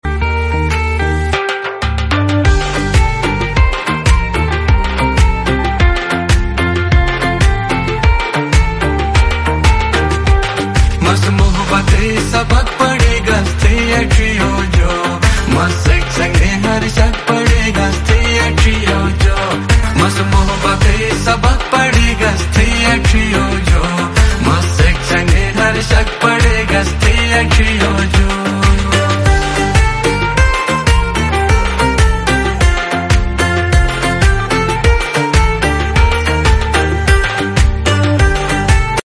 Shina new song 2025 .